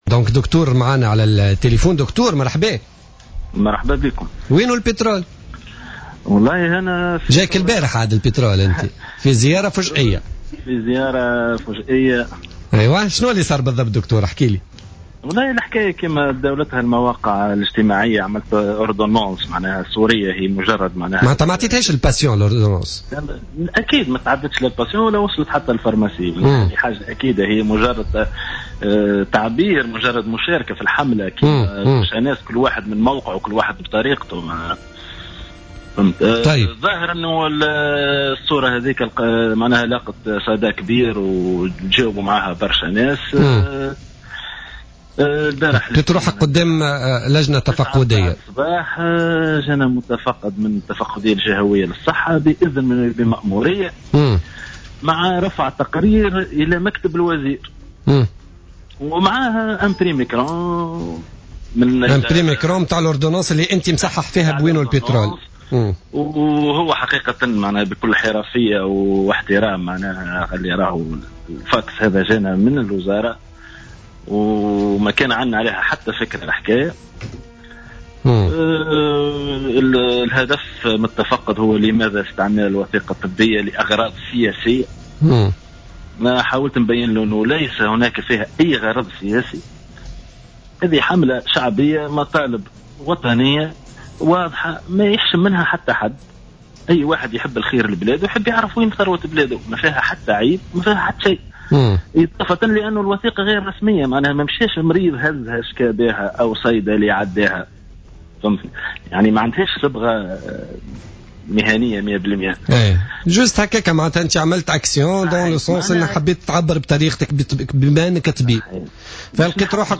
مداخلة له اليوم الثلاثاء في برنامج "بوليتيكا"